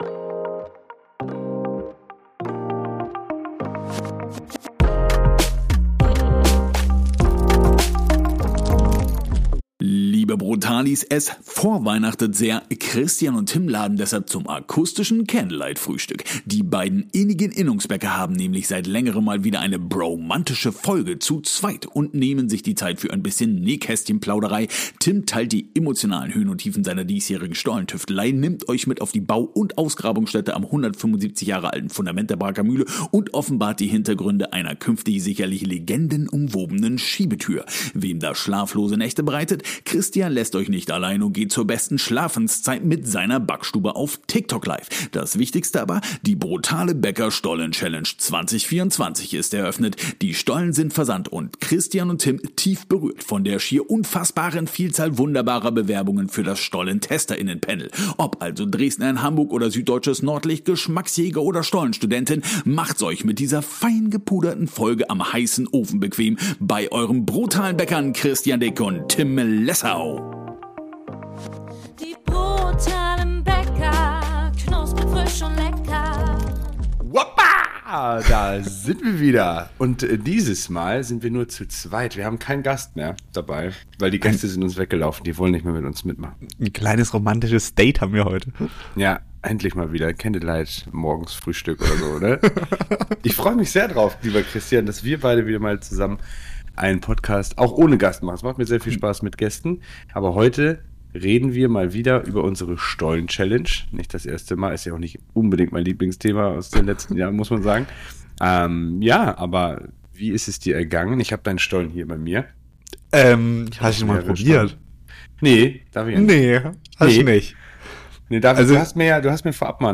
Die beiden innigen Innungsbäcker haben nämlich seit längerem mal wieder eine bromantische Folge zu Zweit – und nehmen sich die Zeit für ein bisschen Nähkästchen-Plauderei.